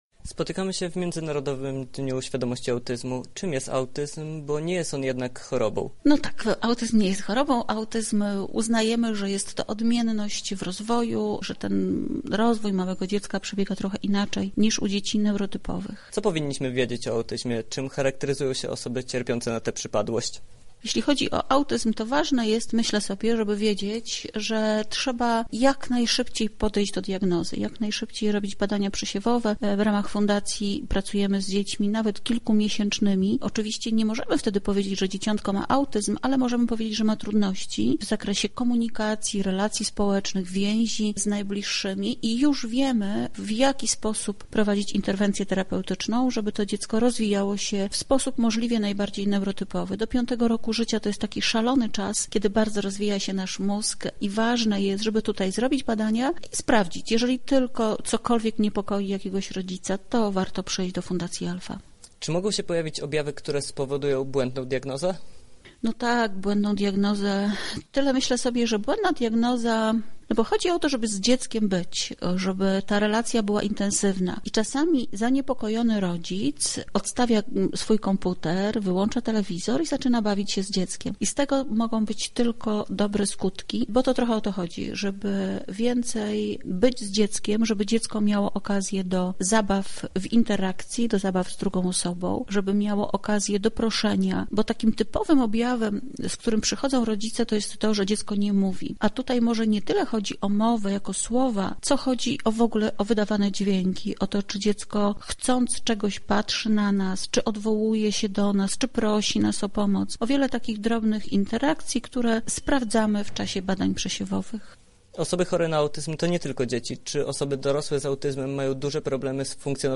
Więcej na ten temat dowiedział się nasz reporter